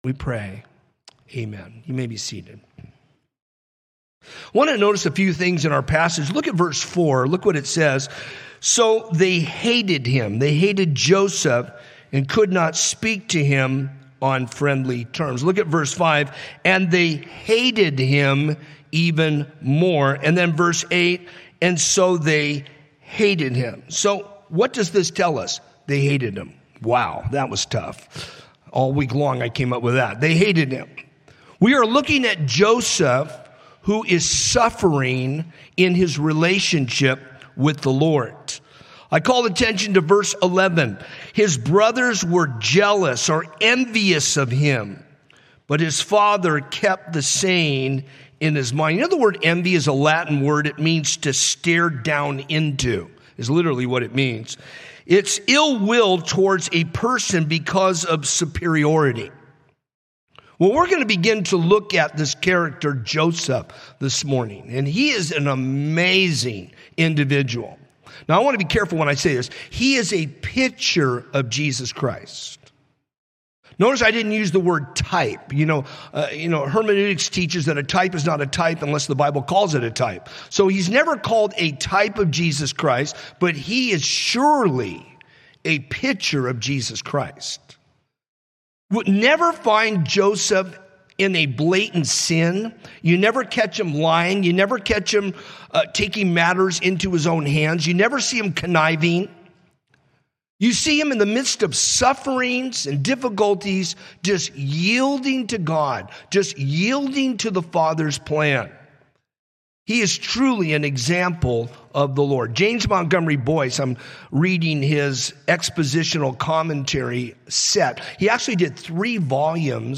A message from the series "In The Beginning…God."